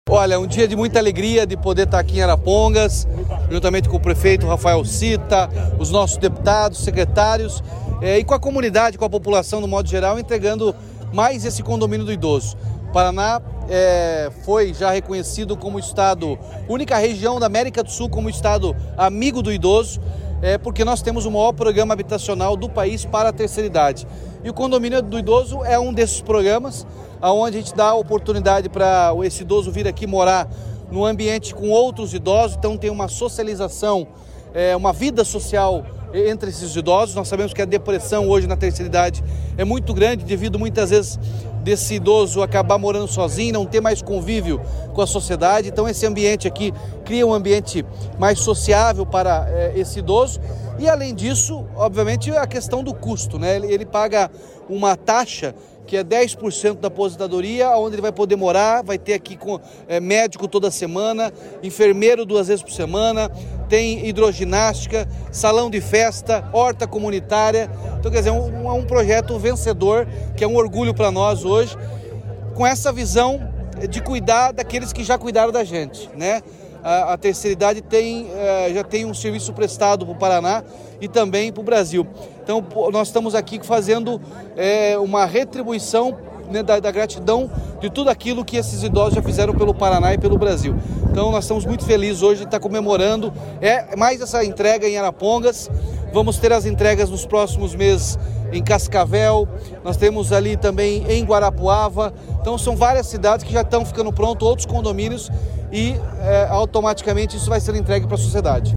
Sonora do governador Ratinho Junior sobre a entrega do Condomínio do Idoso de Arapongas